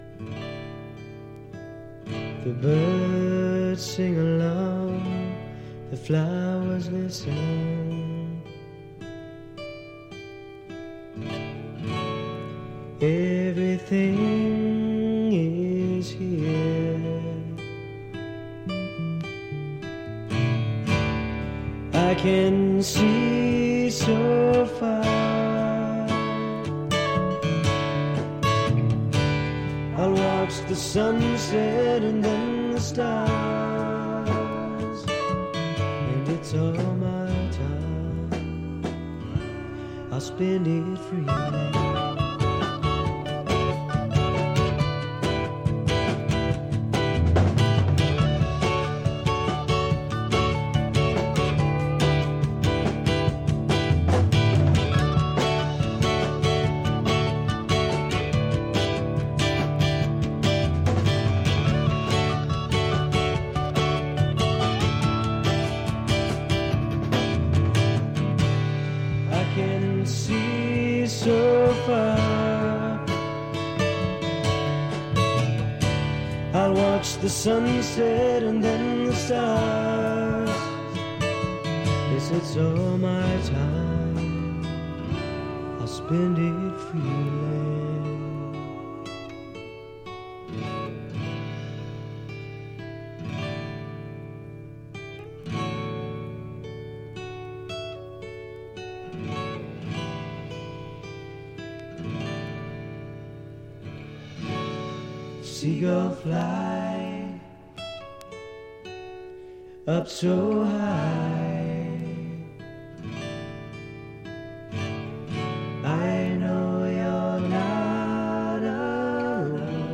美しいメロディー、ハーモニーに思わず聴き入ってしまう傑作揃いです！